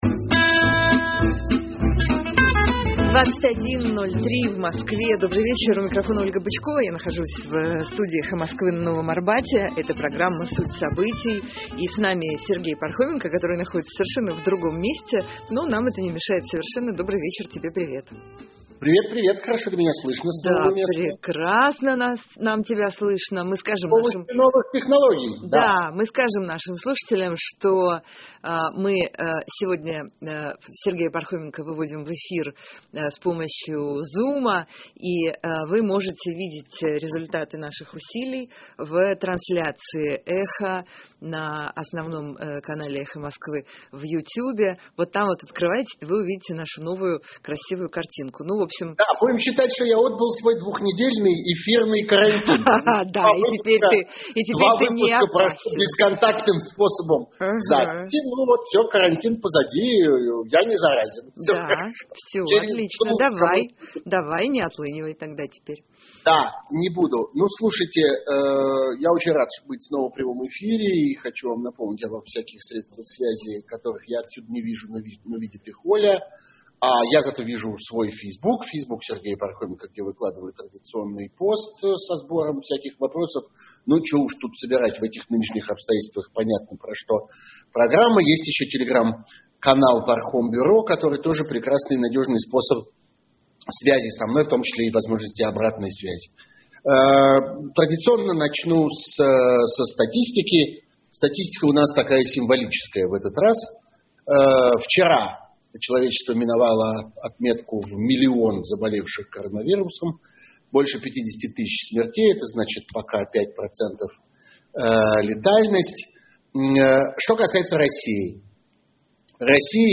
Я нахожусь в студии «Эхо Москвы» на Новом Арбате.
И с нами Сергей Пархоменко, который находится в совершенно другом месте, но нам это не мешает.